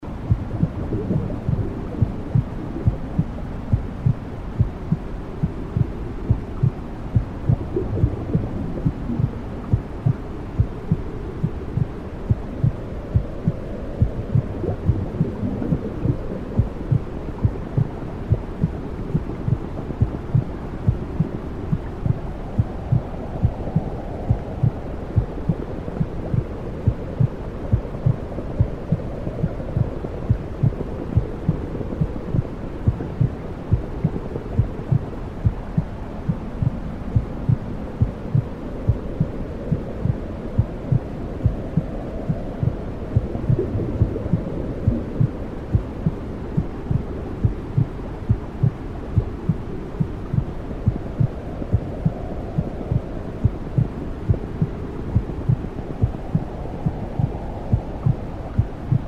Des sons familiers qui rappellent le ventre maternel et procurent une sensation de sécurité.
Bruits apaisants du Cœur et de l’Utérus
01.-womb_sounds-heart-beat.mp3